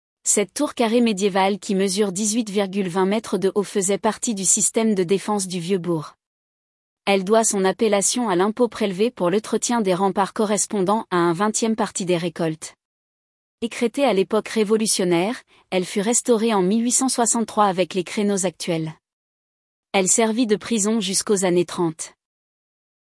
audio guide de la Tour du Vingtain